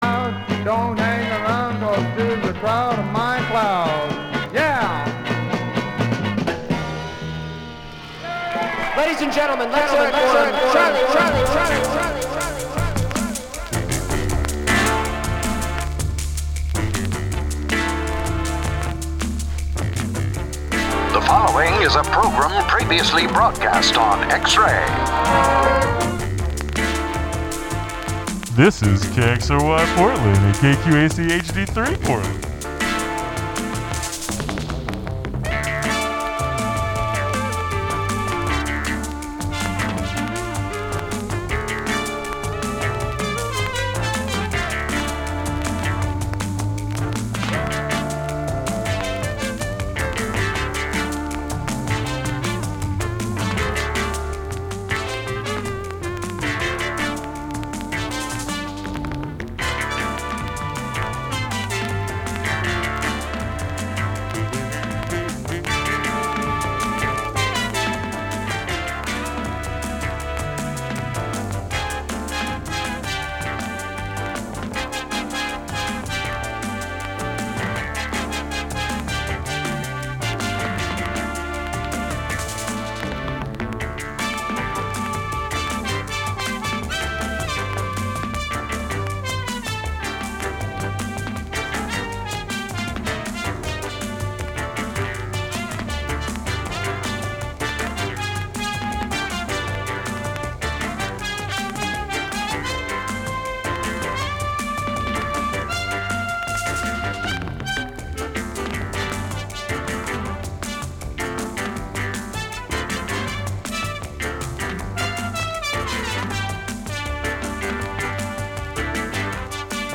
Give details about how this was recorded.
All original vinyl, from all over the world.